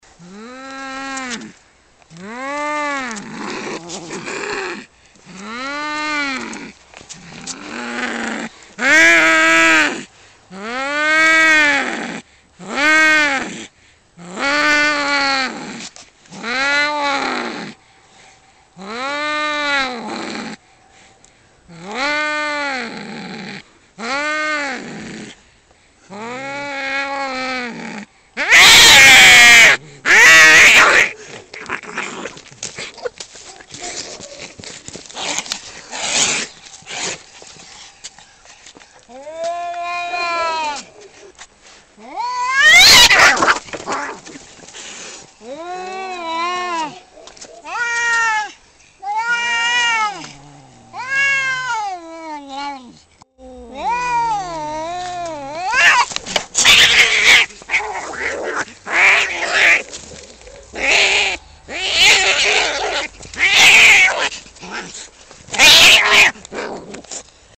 Звуки агрессивной кошки
Звук жёсткой кошачьей драки